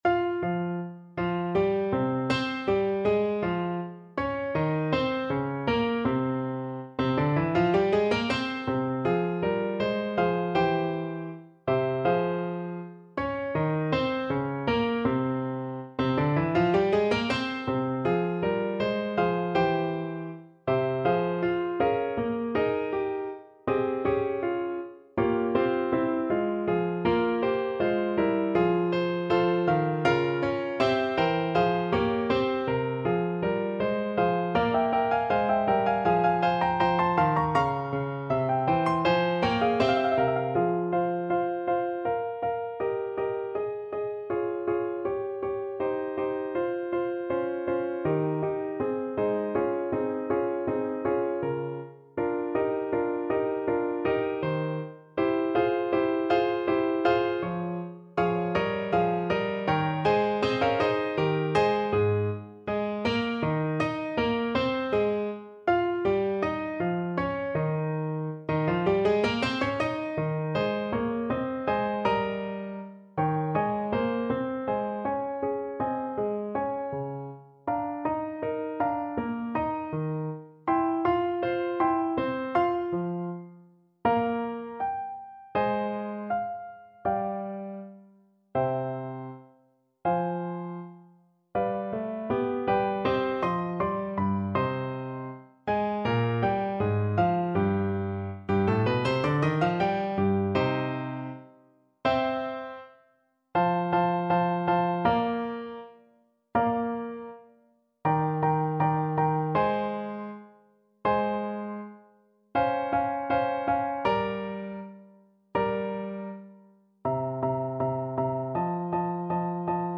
Play (or use space bar on your keyboard) Pause Music Playalong - Piano Accompaniment Playalong Band Accompaniment not yet available transpose reset tempo print settings full screen
4/4 (View more 4/4 Music)
F minor (Sounding Pitch) D minor (Alto Saxophone in Eb) (View more F minor Music for Saxophone )
Andante e spiccato
Classical (View more Classical Saxophone Music)